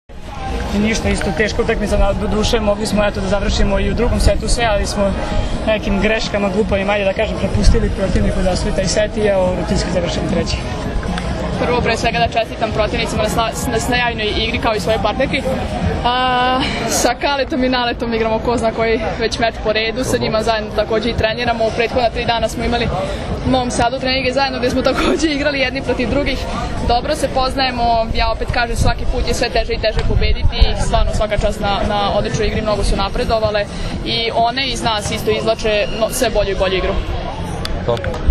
IZJAVE